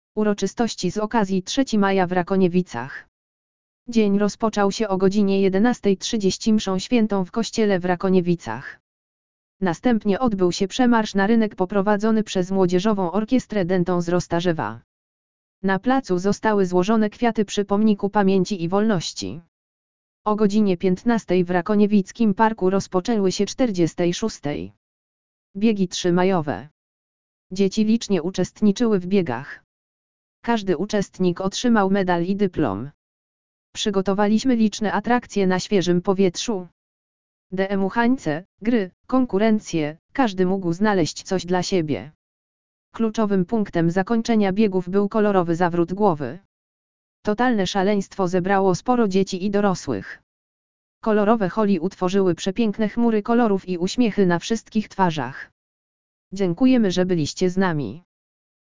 Uroczystości z okazji 3 Maja w Rakoniewicach.
uroczystosci_z_okazji_3_maja_w_rakoniewicach.mp3